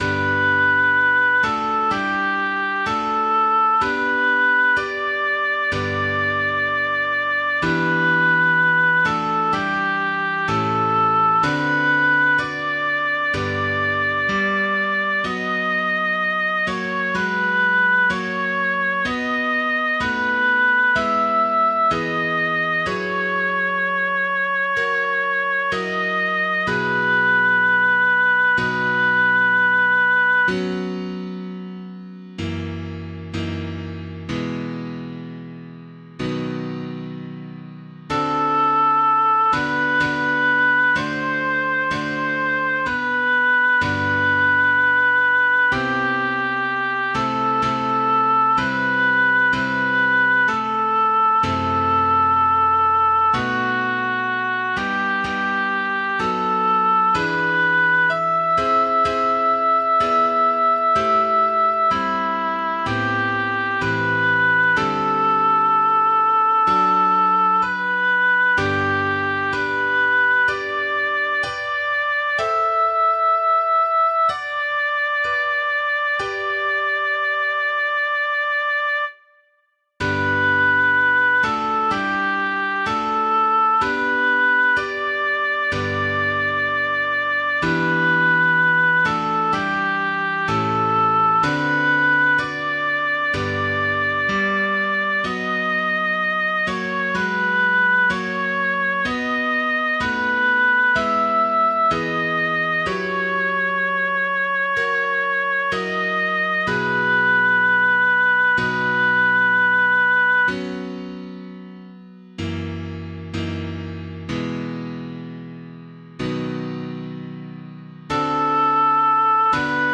ave maris stella-s2.mp3